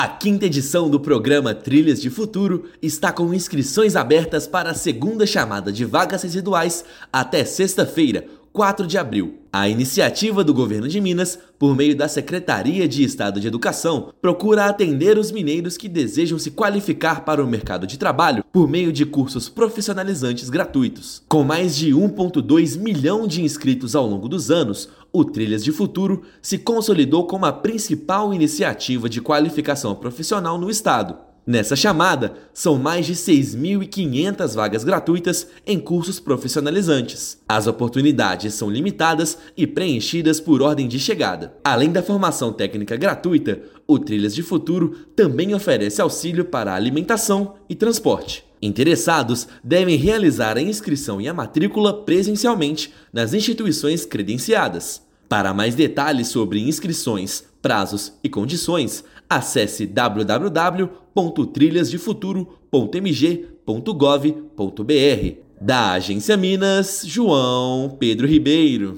Prazo de inscrições da 2ª chamada vai até sexta-feira (4/4), com a oferta de mais de 6,5 mil vagas gratuitas em cursos profissionalizantes. Ouça matéria de rádio.